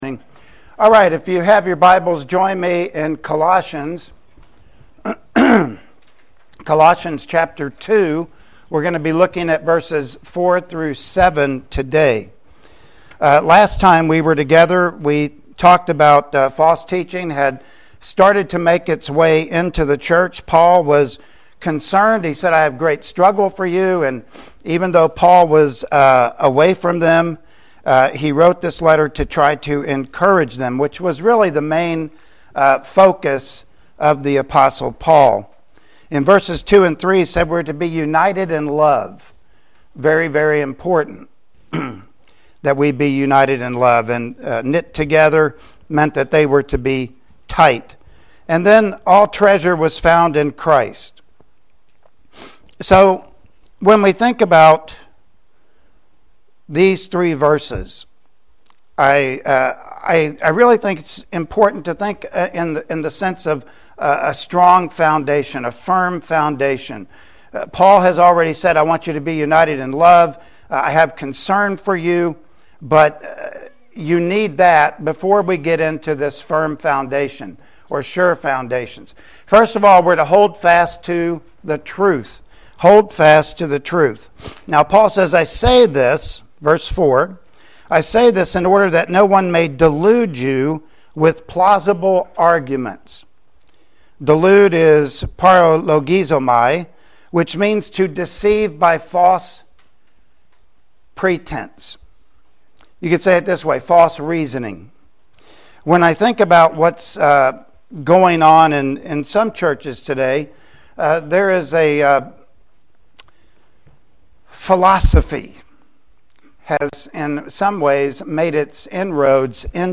Passage: Colossians 2:4-7 Service Type: Sunday Morning Worship Service Topics